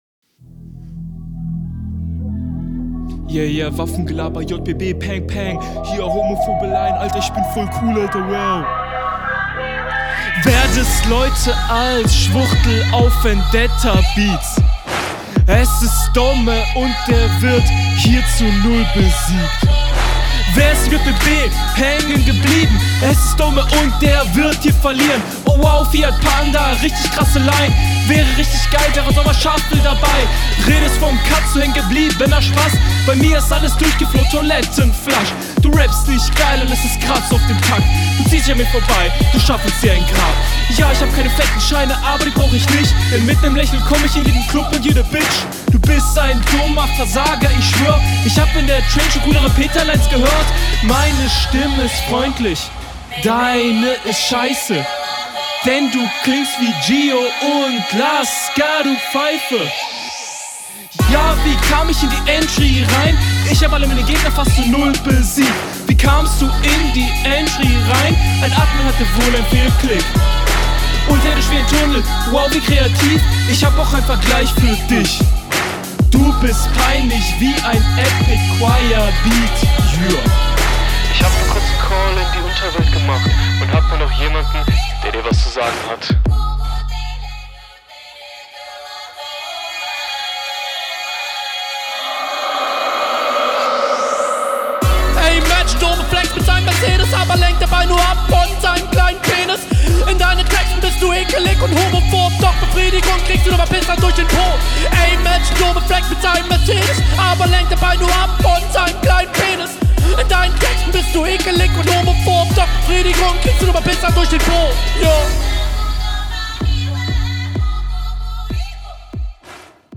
Das Intro ist ganz nett soweit, der Einstieg in Halftime ist soundtechnisch dann aber sehr …